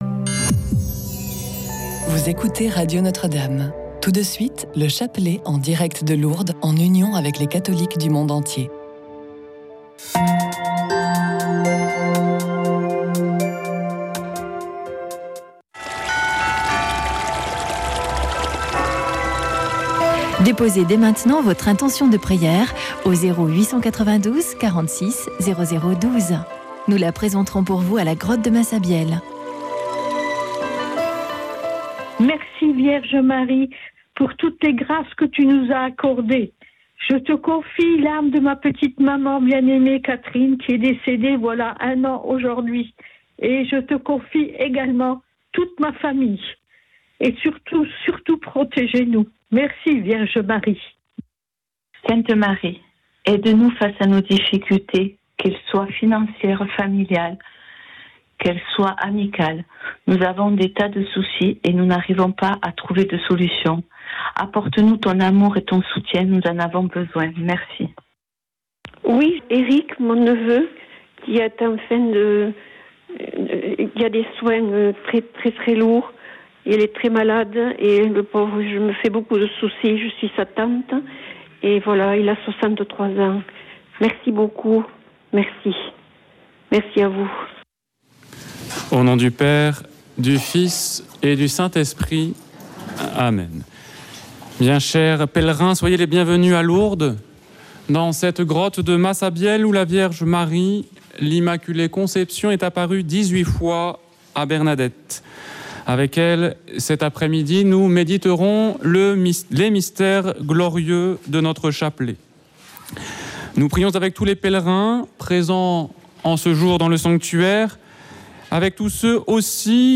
Chapelet de Lourdes - Chapelet de Lourdes